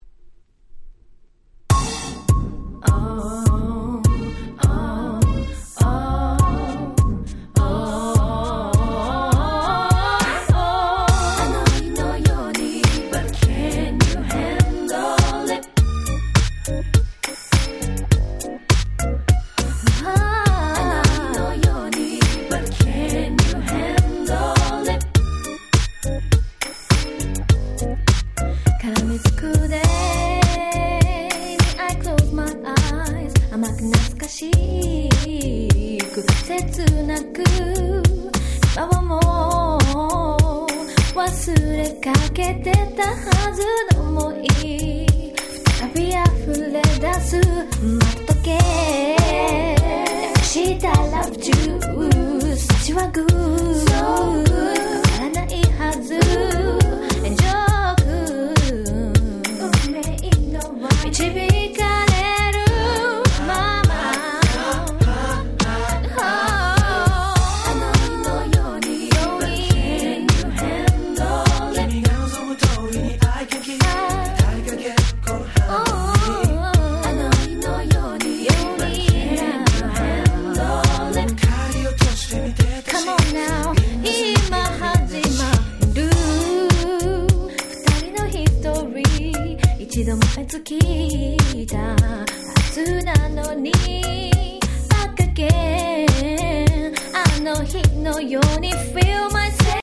00' Smash Hit Japanese R&B !!